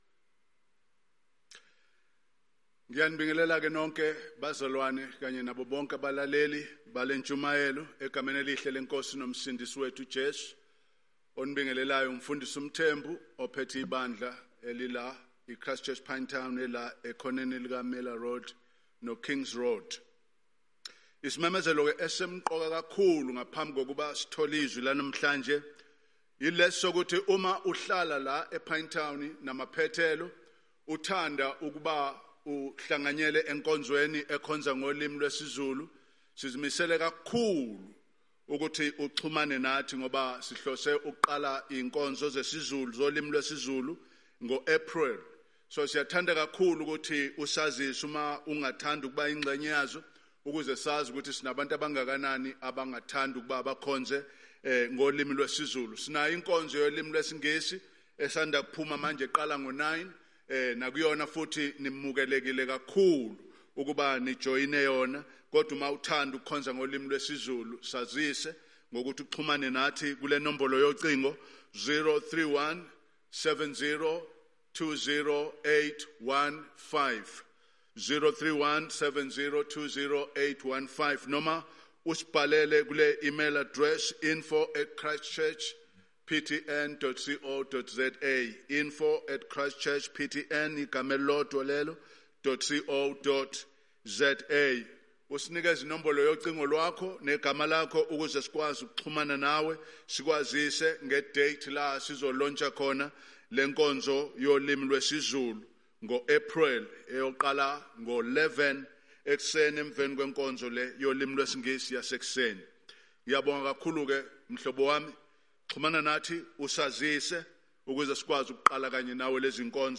Passage: Hebrews 4:14-16 Event: Zulu Sermon « Grace is Greater than all our sin God’s Work and Ours